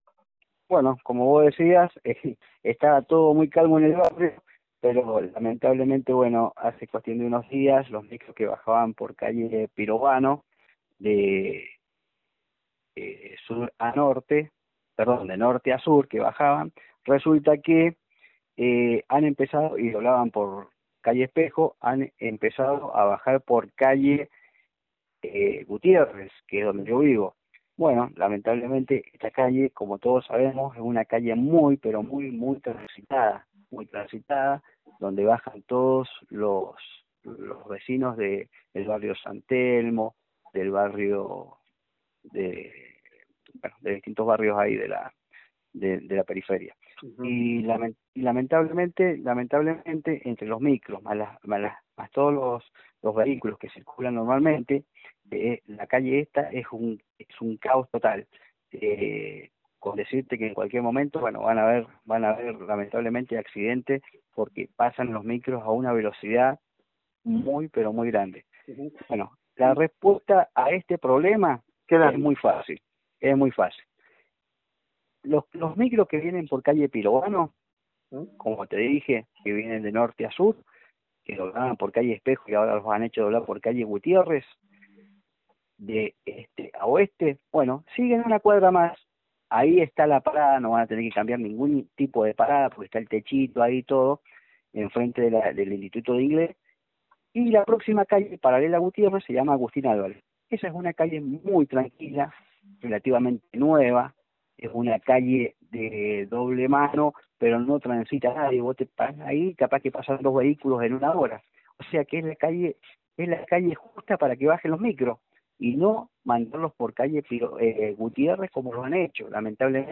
A continuación dejamos la entrevista